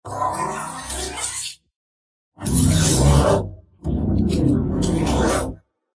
monster-flange.ogg